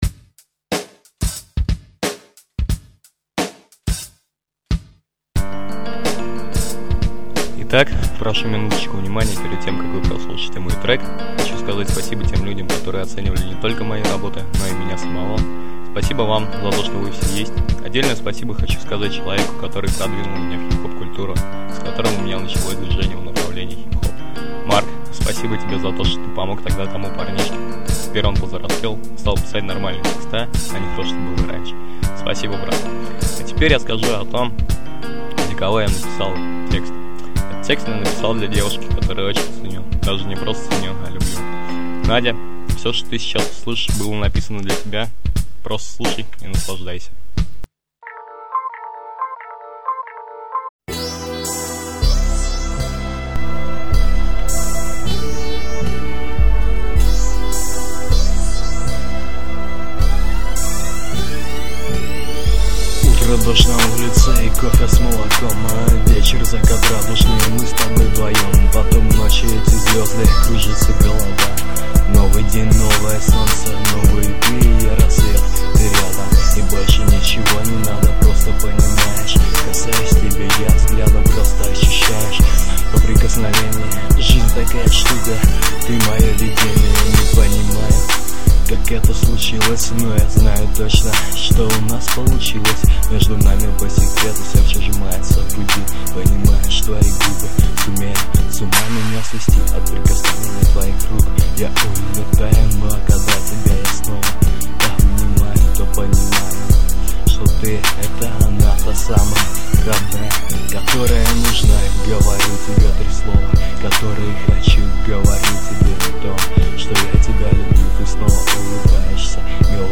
Вообще не плохо довольно таки,но как то тя тихо слышно на фоне музыки,или мне это только кажется?
тихо слышно из-за того что писал через непосредственно прогу через которую пишется музыка)